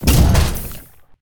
CosmicRageSounds / ogg / general / combat / battlesuit / move2.ogg